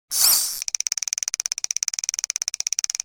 fishreel.wav